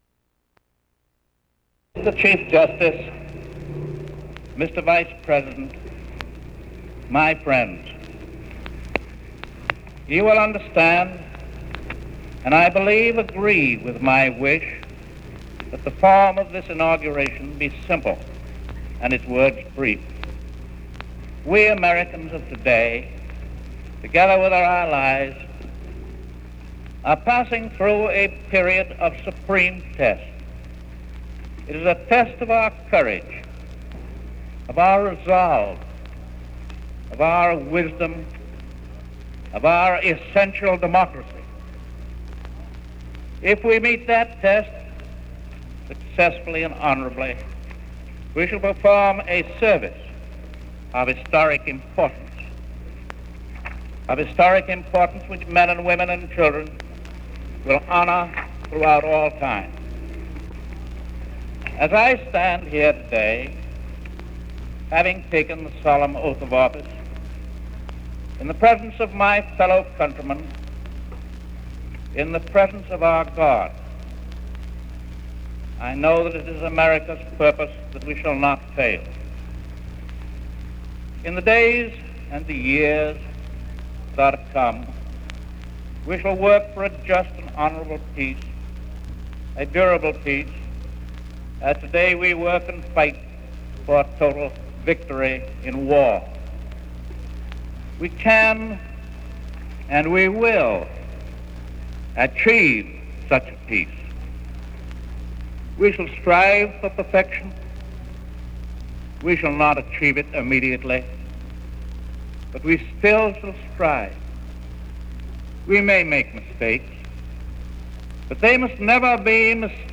U.S. President Franklin D. Roosevelt's fourth inaugural address